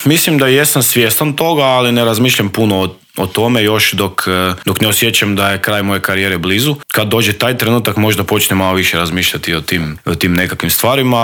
Vrhunski hrvatski gimnastičar bio je gost Intervjua tjedna Media servisa.